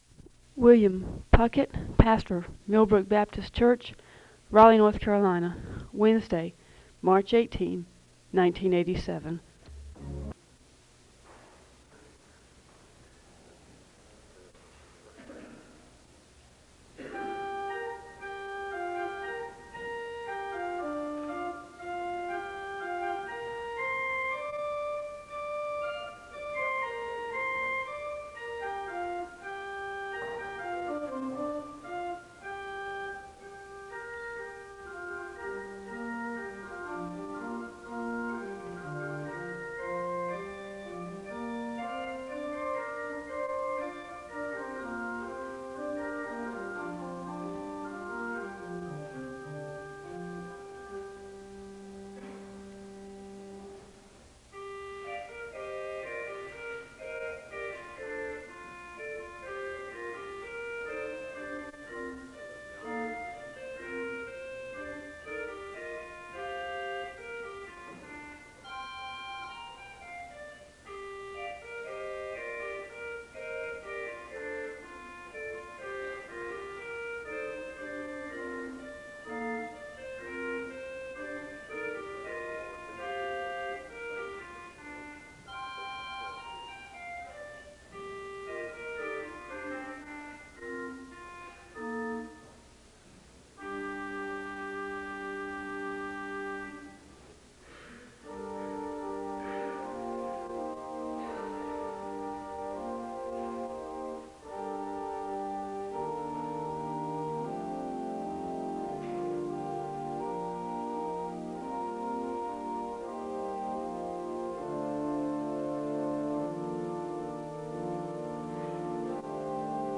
The service begins with organ music (0:00-3:56). There is a moment of prayer (3:57-5:06). There is a responsive reading (5:07-6:24).
The choir sings an anthem (7:27-12:49).